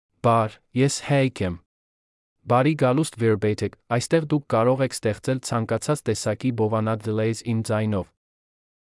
HaykMale Armenian AI voice
Hayk is a male AI voice for Armenian (Armenia).
Voice sample
Listen to Hayk's male Armenian voice.
Male
Hayk delivers clear pronunciation with authentic Armenia Armenian intonation, making your content sound professionally produced.